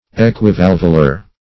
Equivalvular \E`qui*val"vu*lar\
equivalvular.mp3